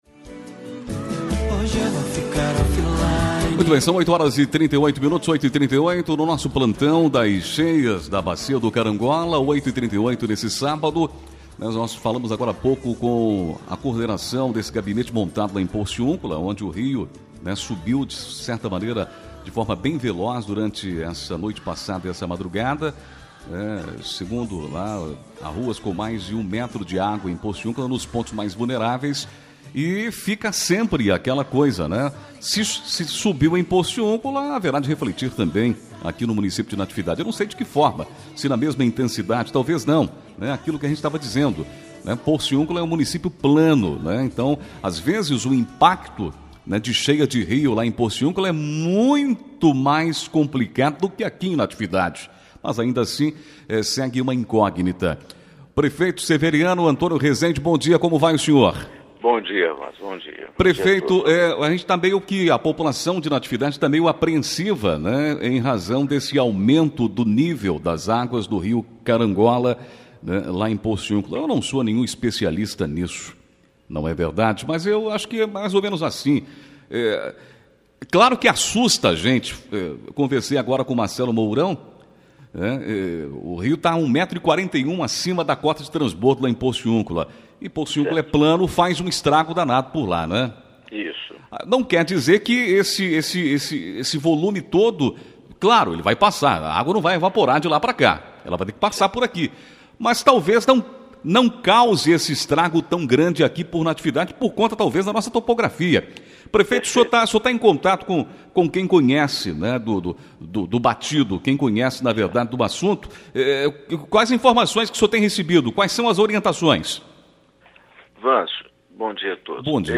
20 fevereiro, 2021 DESTAQUE, ENTREVISTAS
Em entrevista à Rádio Natividade na manhã deste sábado (20), o prefeito Severiano Rezende falou das ações que já estão sendo tomadas, por conta do iminente transbordo do Rio Carangola, previsto para as próximas horas na cidade. Segundo ele, apesar de pontos de alagamentos serem inevitáveis, o volume deverá ficar bem abaixo do visto na última enchente no início de 2020.
ENTREVISTA-SEVERIANO.mp3